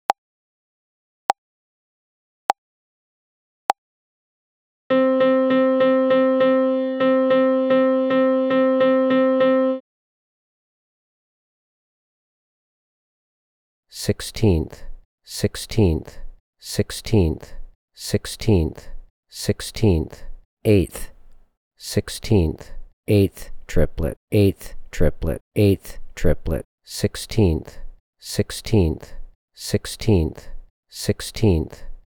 • Level 11: More work with Triplet and Sixteenth Note Rhythms in 4/4.
Find examples below for each level of the voice answer MP3s:
Rhy_ET_L11_50BPM-1.mp3